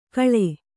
♪ kaḷe